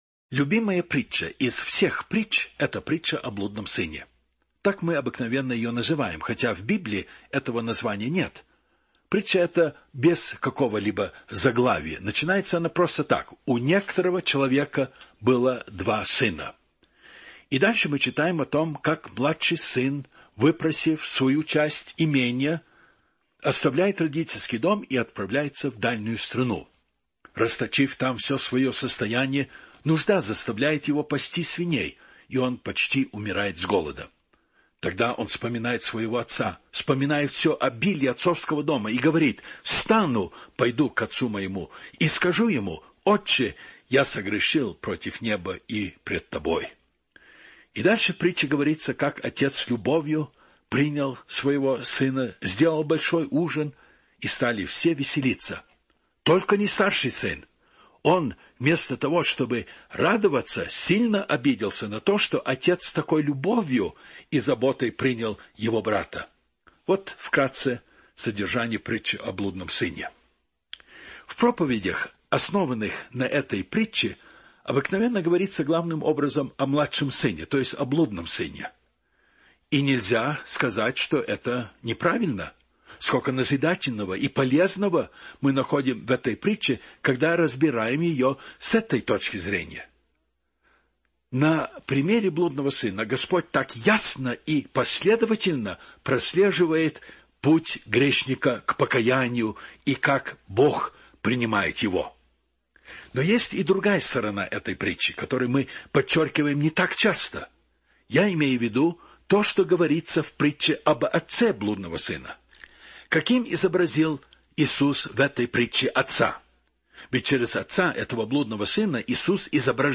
без музыки